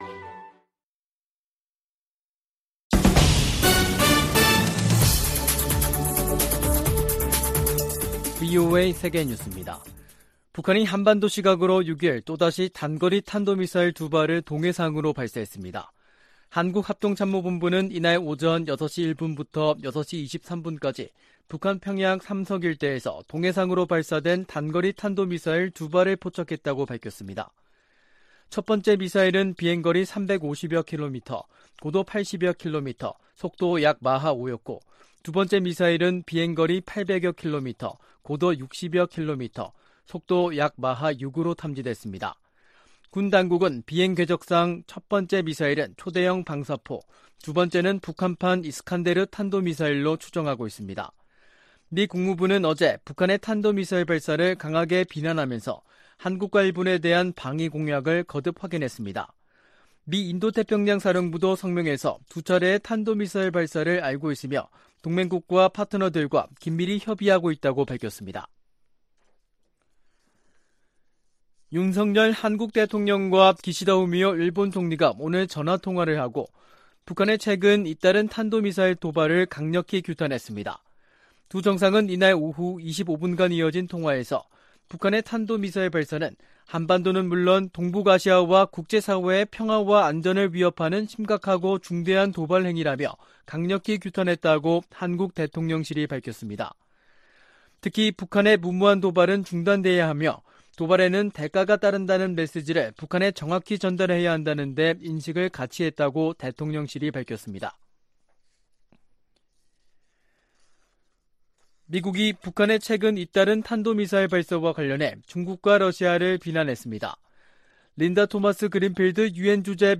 VOA 한국어 간판 뉴스 프로그램 '뉴스 투데이', 2022년 10월 6일 2부 방송입니다. 북한이 또 다시 단거리 탄도미사일 두발을 동해상으로 발사했습니다. 유엔 안보리가 북한의 탄도미사일 발사에 대응한 공개회의를 개최하고 북한을 강하게 규탄했습니다.